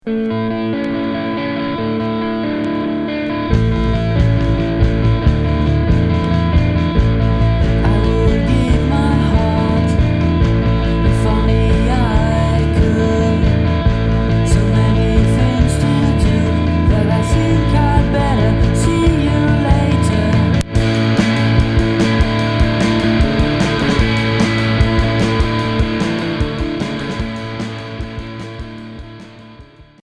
Noisy pop